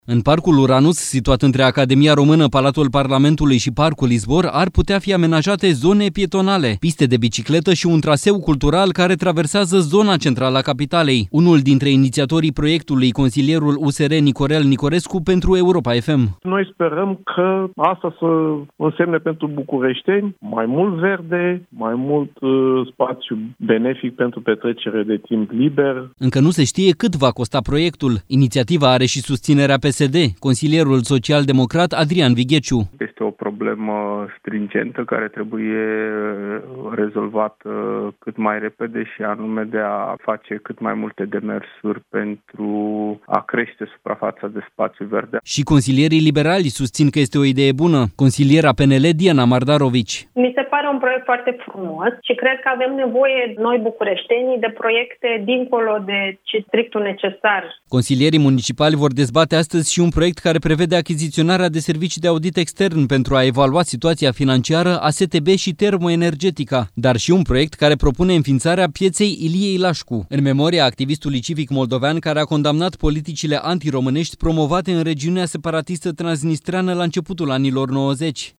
Unul dintre inițiatorii proiectului, consilierul USR, Nicorel Nicorescu, a spus pentru Europa FM că proiectul va însemna creșterea suprafețelor de spațiu verde în Capitală.
„Noi sperăm că asta să însemne pentru bucureșteni mai mult verde, mai mult spațiu benefic pentru petrecere de timp liber”, a spus consilierul USR Nicorel Nicorescu.
Și consilierii liberali susțin că este o idee bună: „Ni se pare un proiect foarte frumos și cred că avem nevoie noi, bucureștenii, dincolo de strictul necesar”, a spus și consiliera PNL, Diana Mardarovici.